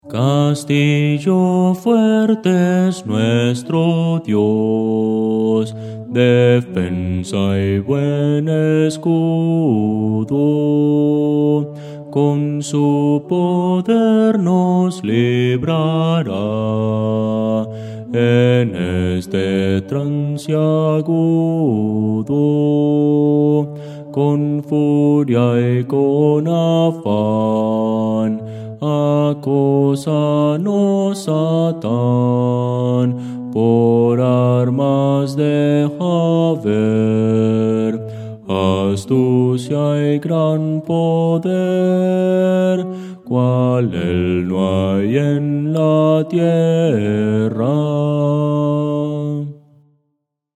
Voces para coro
Bajo – Descargar
Audio: MIDI